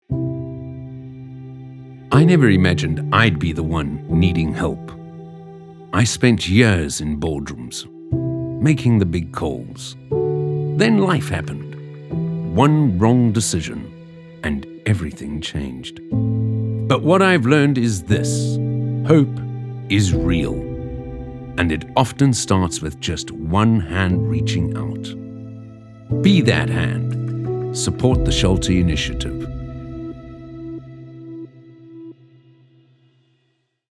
articulate, authentic, captivating, confident, Deep, energetic, friendly
30-45, 45 - Above